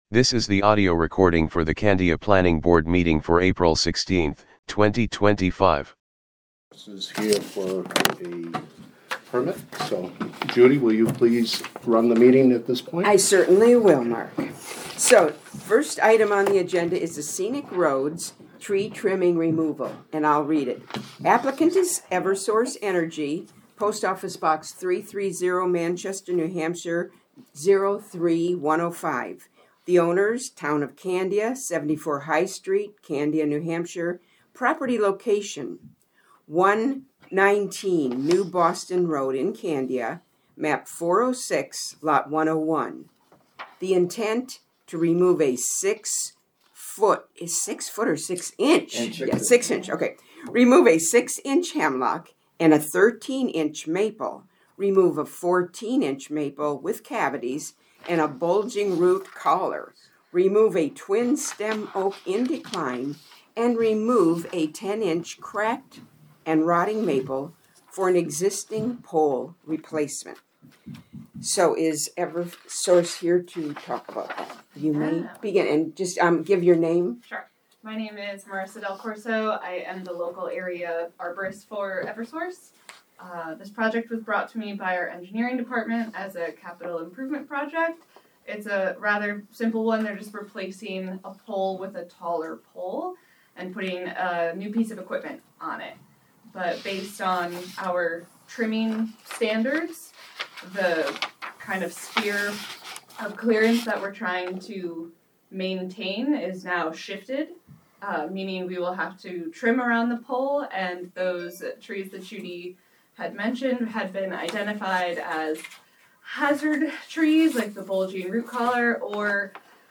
Audio recordings of committee and board meetings.